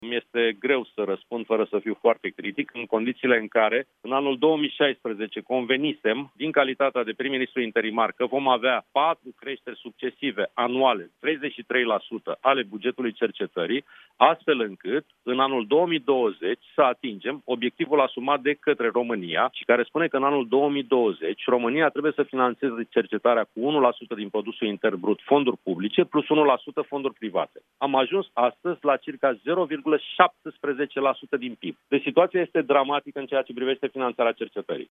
La Europa FM, preşedintele Consiliului Naţional al Rectorilor, Sorin Câmpeanu, admite că cercetarea se află în criză, cu o finanţare dramatic de mică.